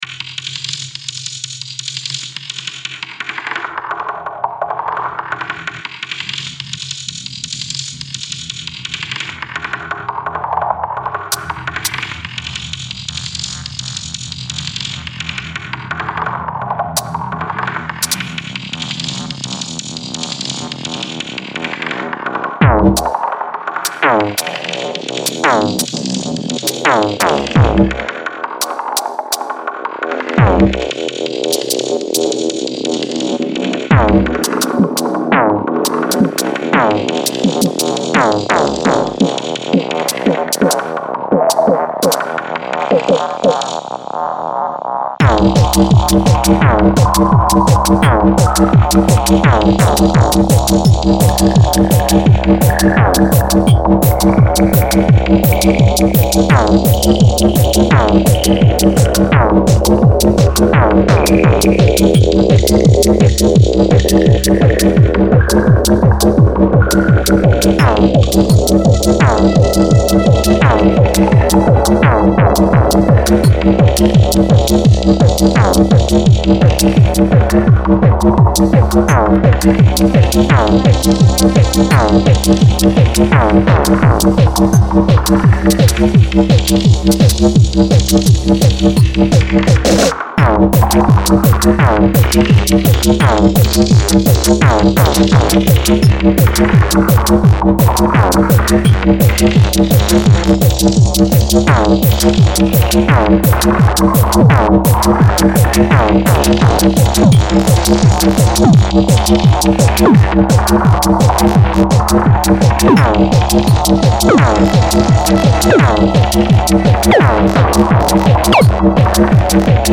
02/02/2015 Etiquetes: TEKNO Descàrregues i reproduccions